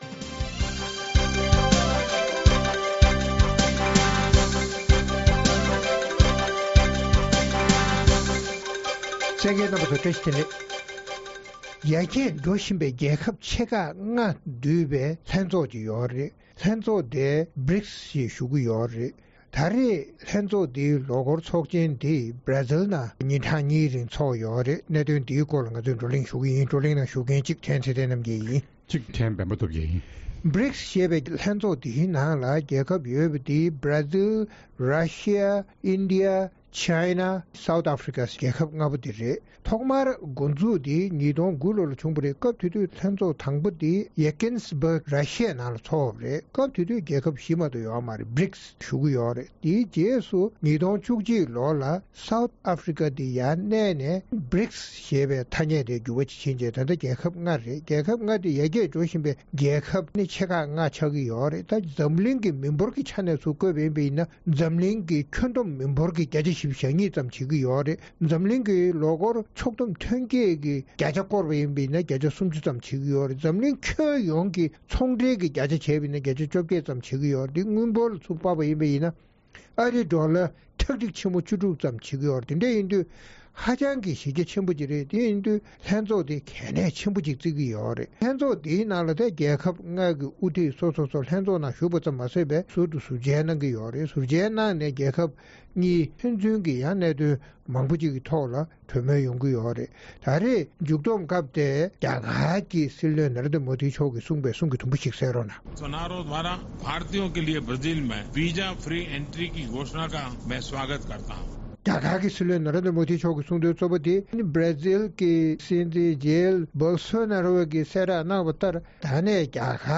རྩོམ་སྒྲིག་པའི་གླེང་སྟེགས་ཞེས་པའི་ལེ་ཚན་ནང་། BRICS ཞེས་ཡར་རྒྱས་འགྲོ་བཞིན་པའི་རྒྱལ་ཁབ་ཆེ་ཁག་ལྔའི་དབུ་ཁྲིད་རྣམས་Brazil དུ་ཉིན་གཉིས་རིང་ལོ་འཁོར་ལྷན་ཚོགས་གནང་བའི་གྲོས་གཞི་དང་གྲུབ་འབྲས་སོགས་ཀྱི་སྐོར་རྩོམ་སྒྲིག་འགན་འཛིན་རྣམ་པས་བགྲོ་གླེང་གནང་གསན་རོགས་གནང་།